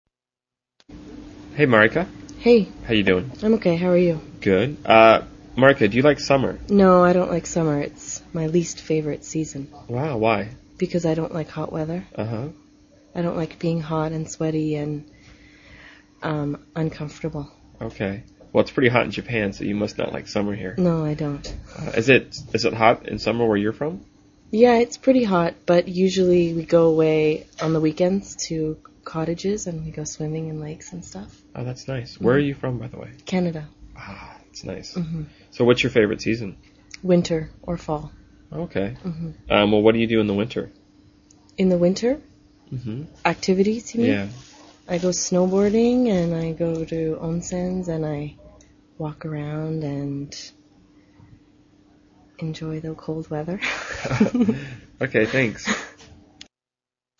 英语高级口语对话正常语速10:气候（MP3）